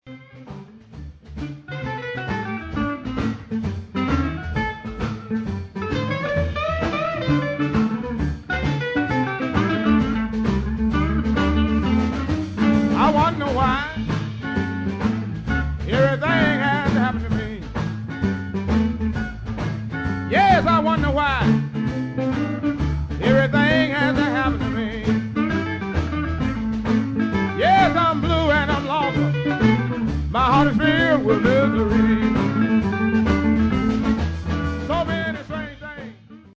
vocals and bass
drums